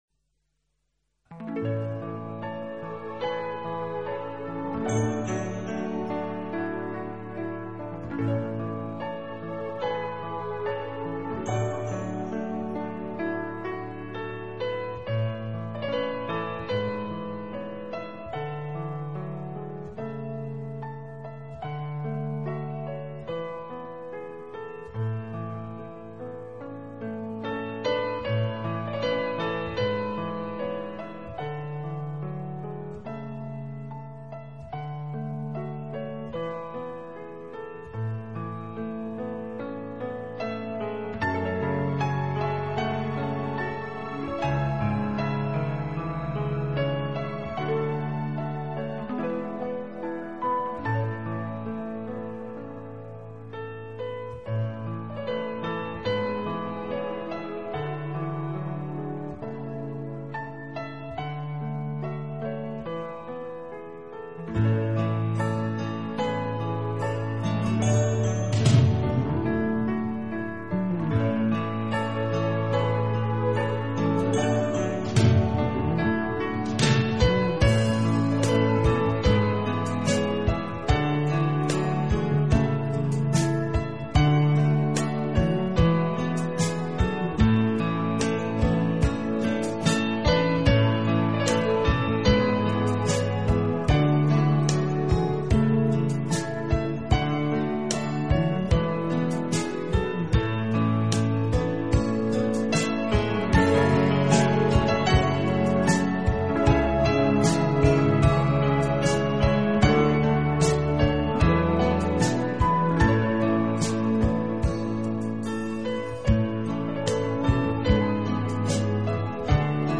【钢琴专辑】
音乐类型：New Age